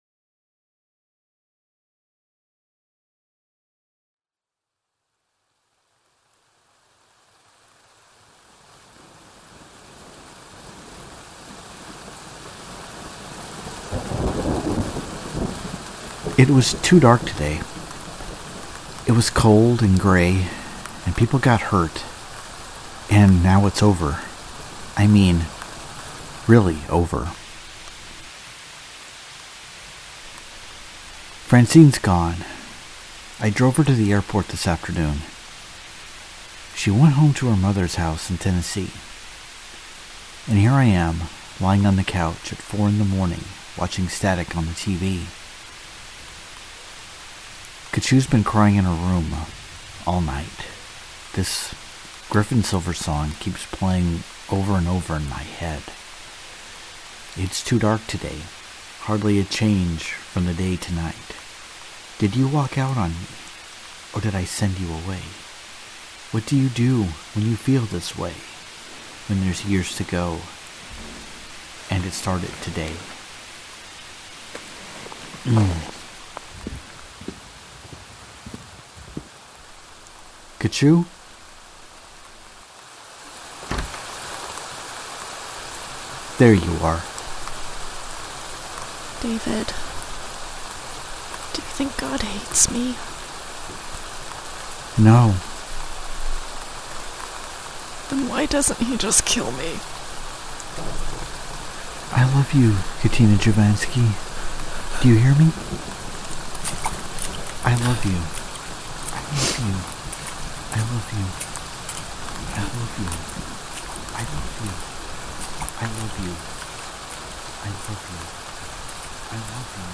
Strangers In Paradise – The Audio Drama – Book 7 – Episode 11 – Two True Freaks